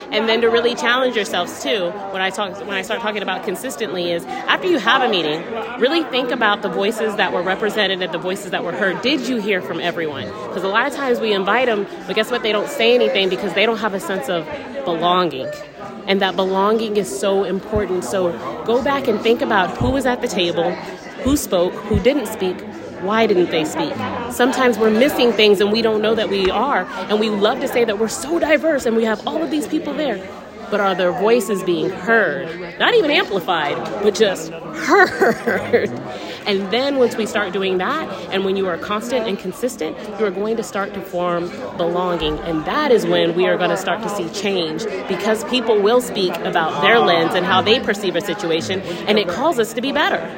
On Sunday afternoon, a crowd gathered at the Grand Avenue United Methodist Church in Salina, for an MLK dedicated event “Mission Possible: Protecting Freedom, Justice and Democracy.”